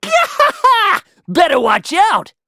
Kibera-Vox_Skill4_a.wav